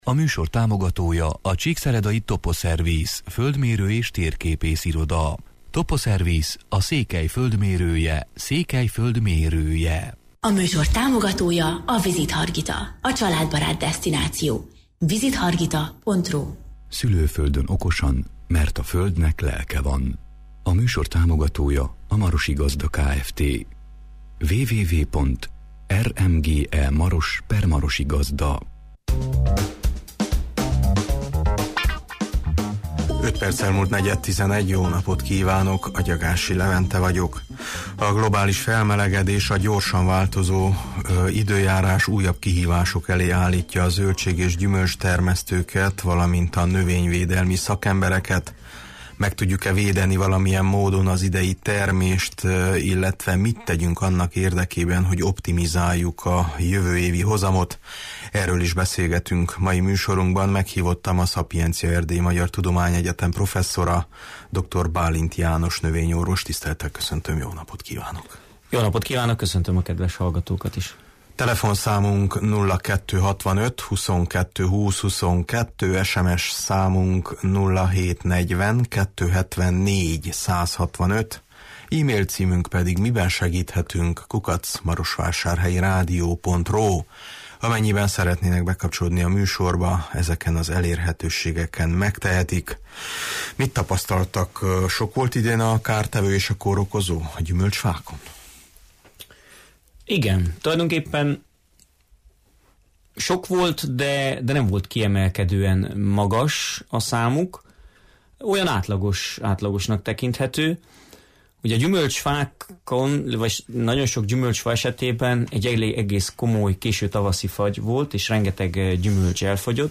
A globális felmelegedés, a gyorsan változó újabb kihívások elé állítja a zöldség- és gyümölcstermesztőket és a növényvédelmi szakembereket. Meg tudjuk-e még védeni valamilyen módon az idei, késő ősszel beérő termést, illetve mit tegyünk annak érdekében, hogy optimizáljuk a jövő évi hozamot – erről is beszélgetünk mai műsorunkban.